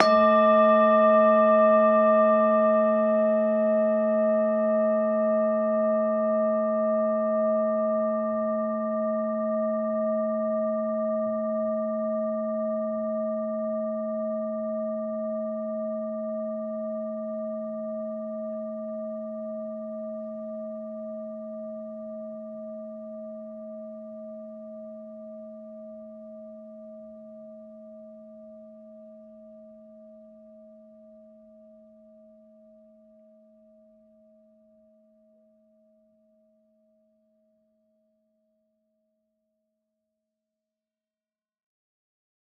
bowl1_mallet2-A3-ff.wav